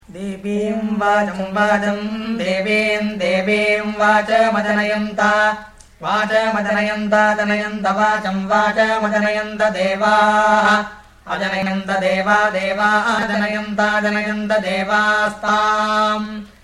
Vikrti Recitation
00-veda2-vkrti-sikha.mp3